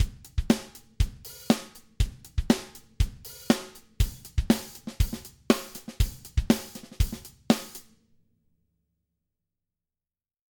Drums – unbearbeitet
eventide_omnipressor_testbericht_dry.mp3